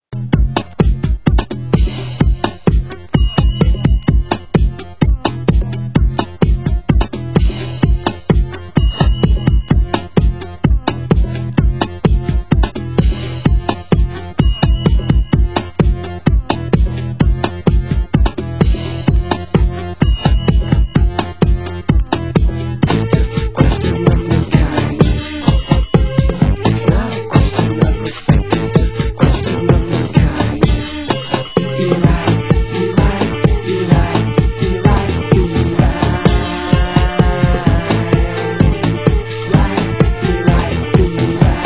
Format: Vinyl 12 Inch
Genre: Tech House